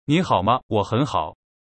步驟2：選擇中文語言，有男聲和女聲，逼真的真人發音。
Notevibes文字轉語音範例：聽聽看文字轉語音的範例：